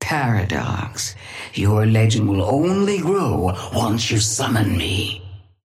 Sapphire Flame voice line - Paradox.
Patron_female_ally_chrono_start_01.mp3